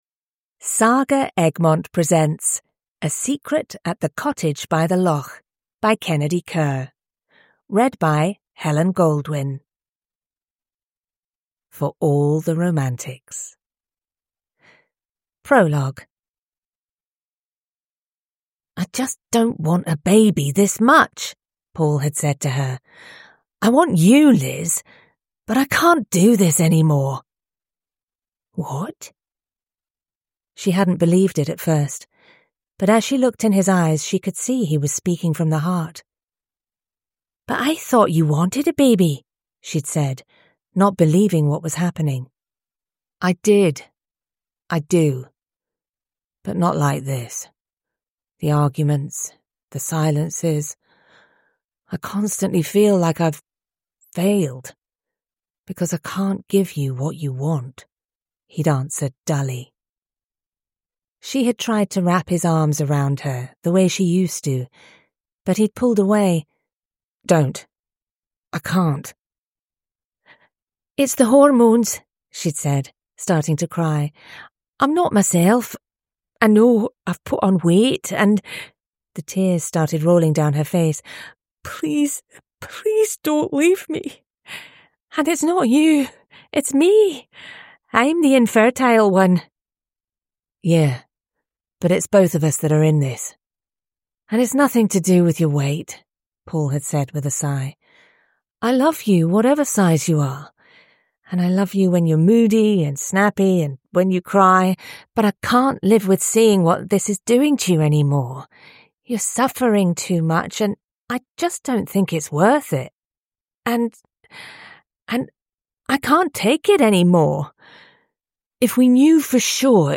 A Secret at the Cottage by the Loch: An uplifting second chance Scottish romance – Ljudbok